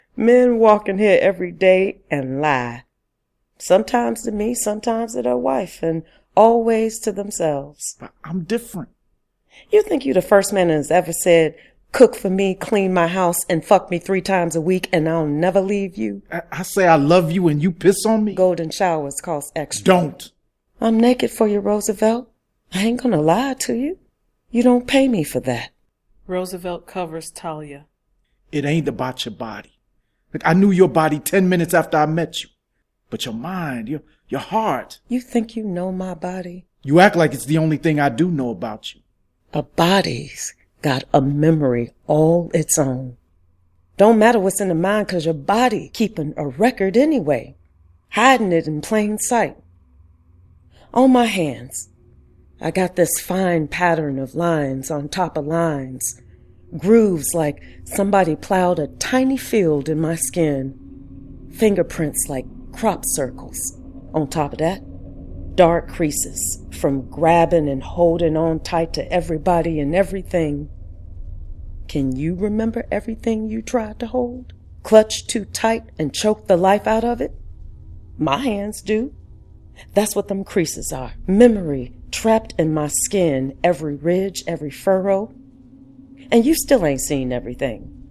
Voiced here by a stellar complement of actors.
Black-Market-Vol.-3-promo.mp3